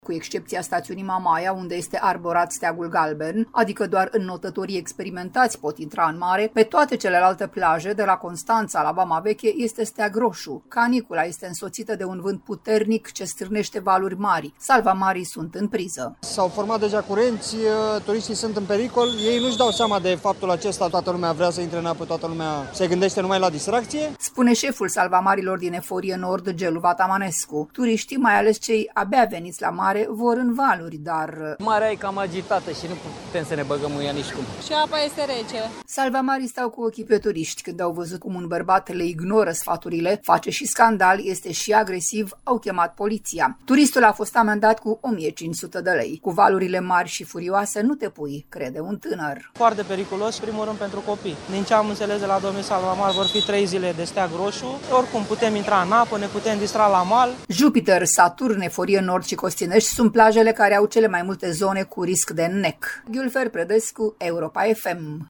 Turist: Este foarte periculos, în special pentru copii